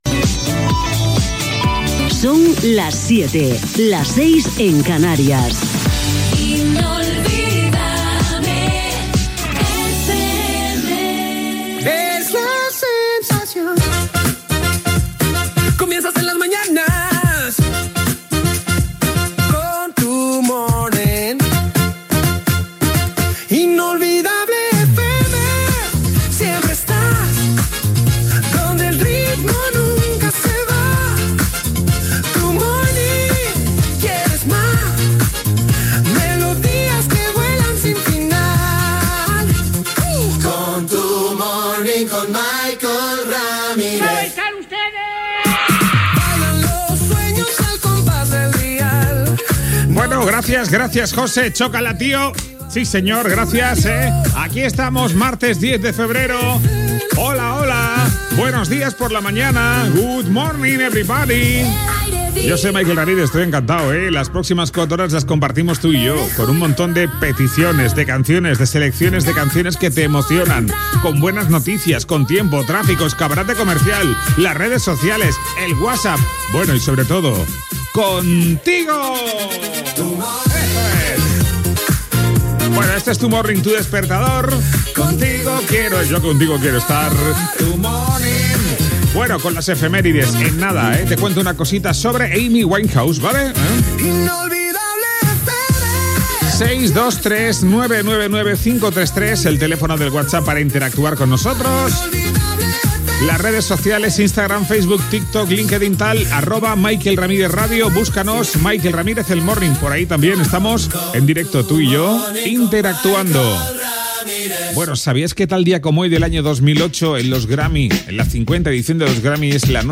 Hora, indicatiu de la ràdio, careta del programa, presentació, efemèride del dia.